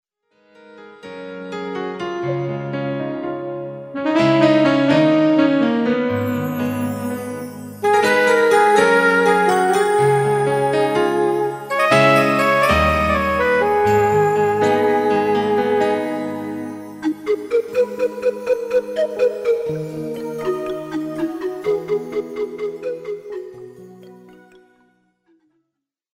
álbum instrumental